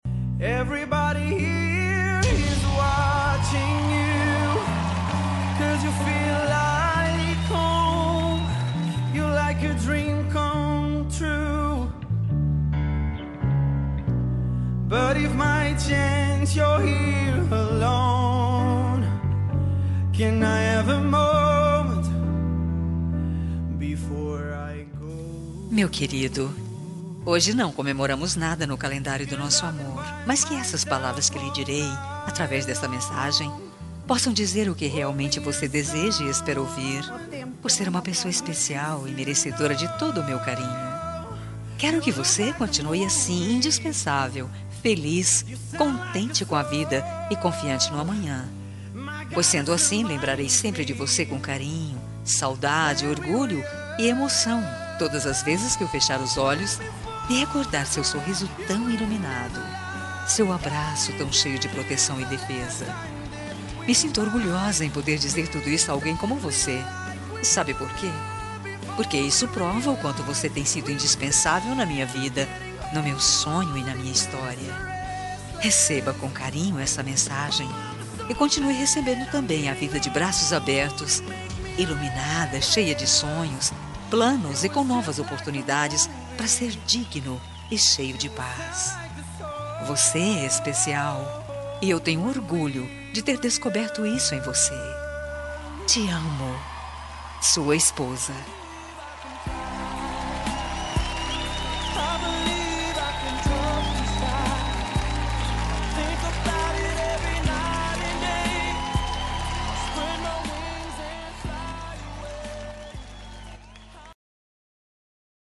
Telemensagem Romântica – Marido Linda – Voz Feminina – Cód: 7866